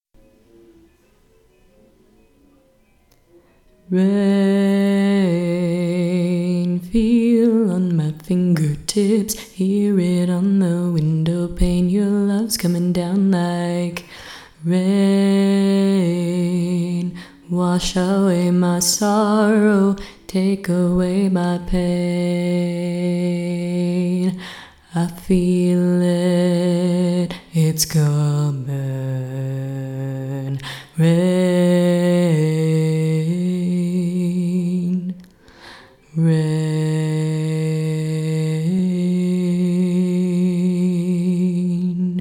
:-P I also apologize for the quality of the tracks.
Each recording below is single part only.